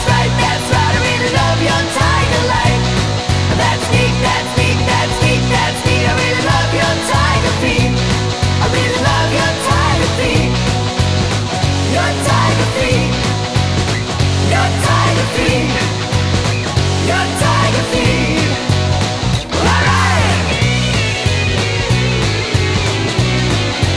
No Guitars Glam Rock 3:52 Buy £1.50